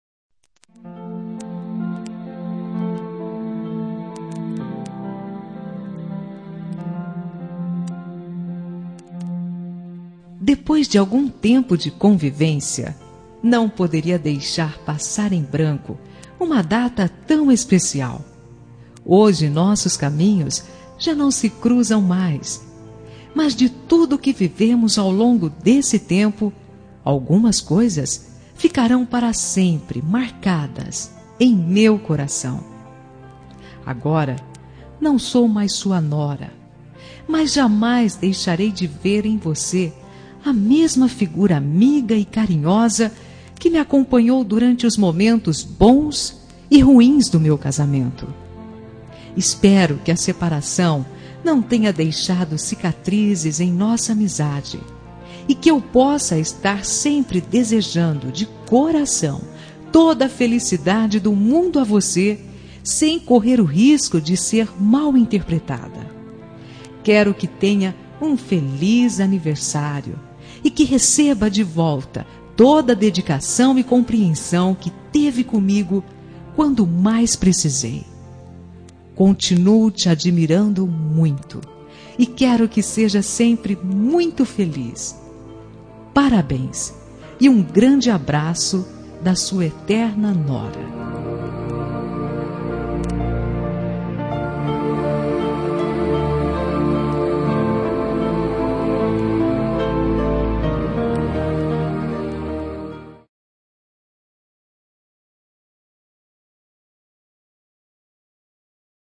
Telemensagem Aniversário de Sogra – Voz Feminina – Cód: 1972 – Ex-sogra